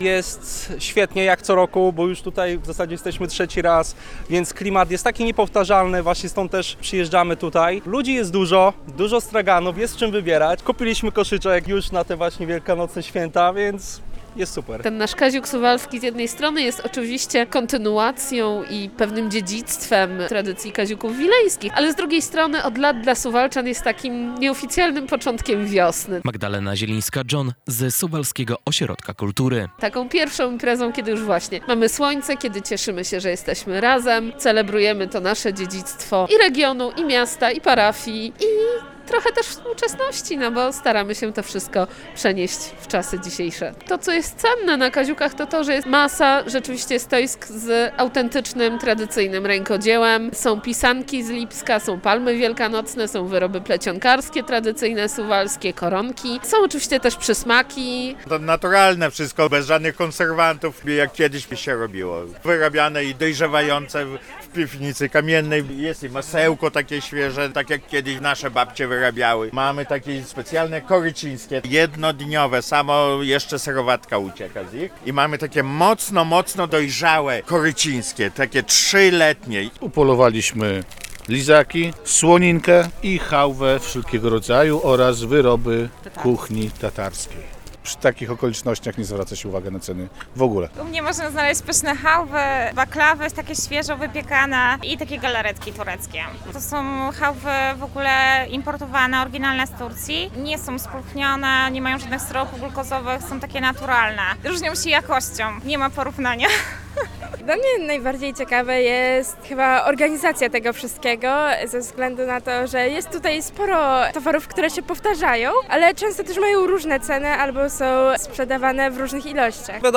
Tłumy na suwalskim Kaziuku. W tym roku na suwalskim Kaziuku wystawiło się ponad 150 wystawców z przeróżnym rękodziełem i jedzeniem.
relacja